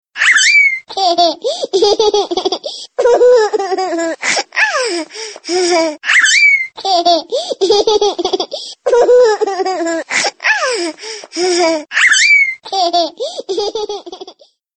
Baby